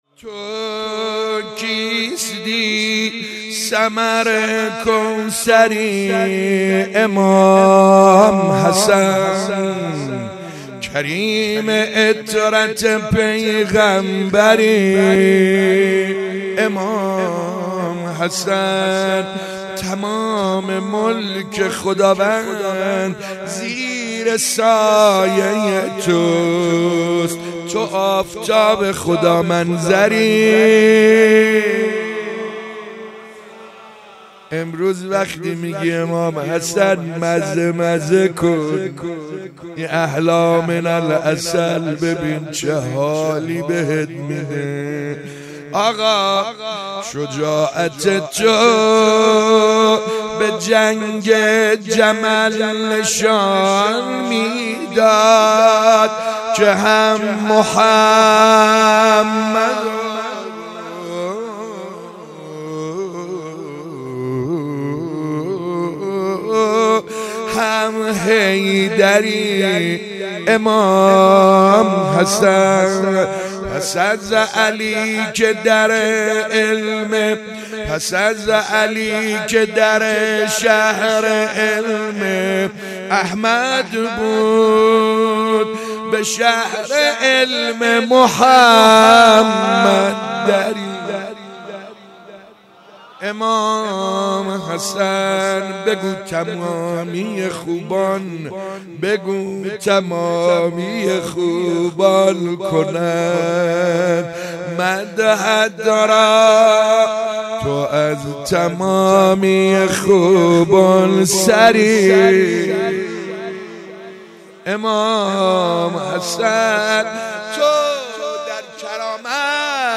روضه - تو کیستی ثمر کوثری